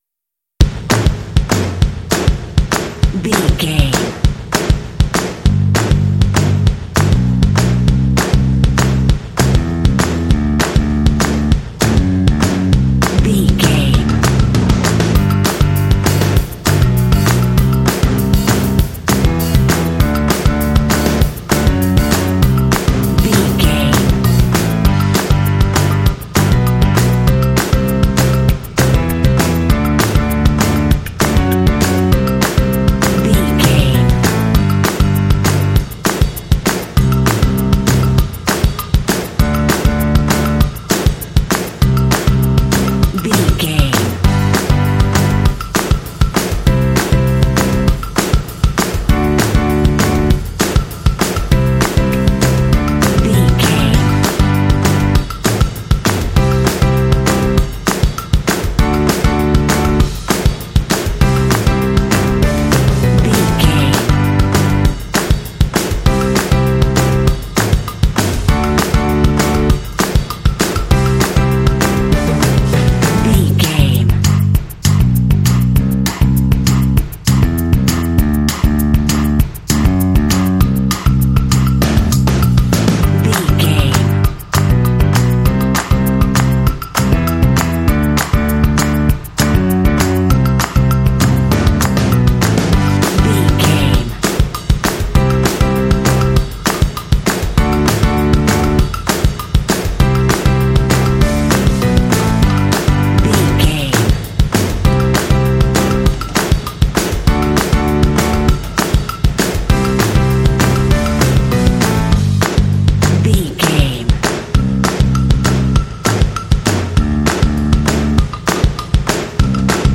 Uplifting
Ionian/Major
cheerful/happy
energetic
lively
drums
percussion
bass guitar
piano
indie